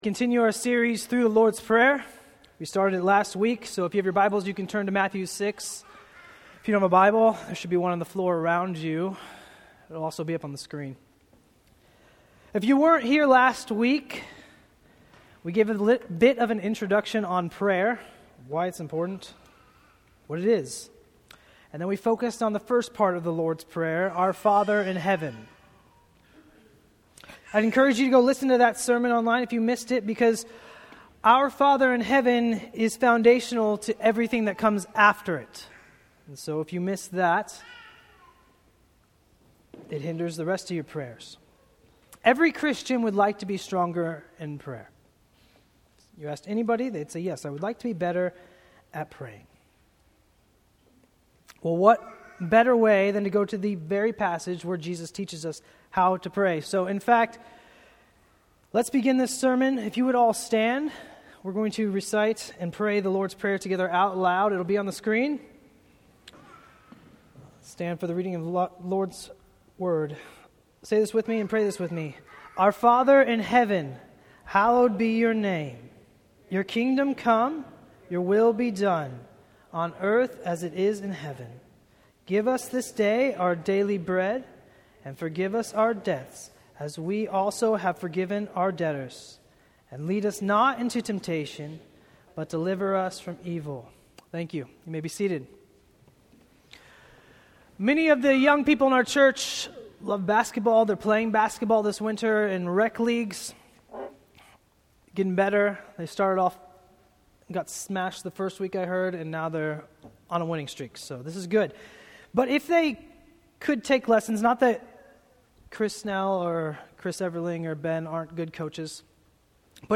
Bloomington Bible Church Sermons